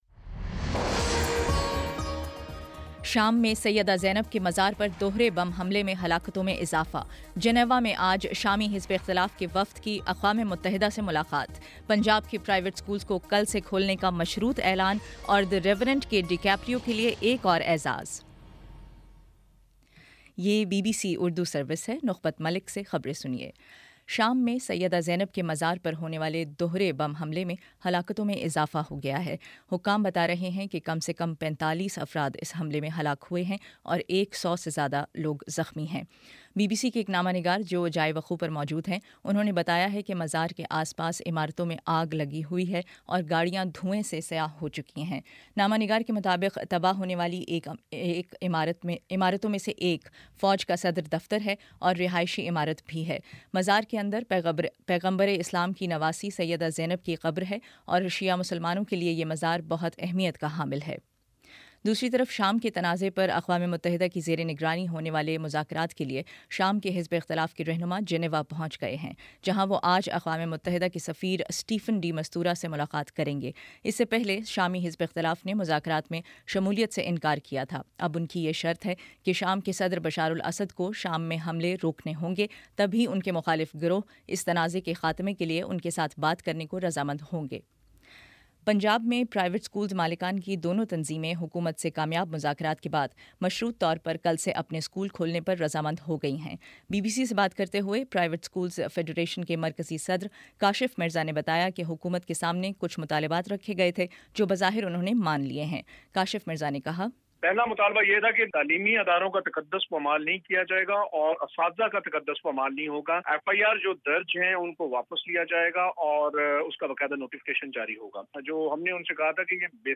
جنوری 31 : شام چھ بجے کا نیوز بُلیٹن